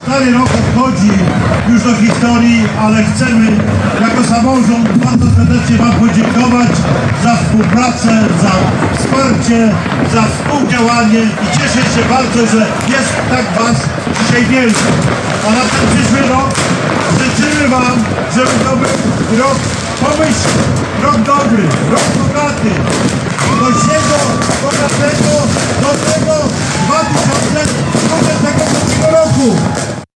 Kilkaset osób witało 2025 rok na Placu Marii Konopnickiej w Suwałkach.
Życzenia noworoczne złożył mieszkańcom miasta Czesław Renkiewicz, prezydent Suwałk.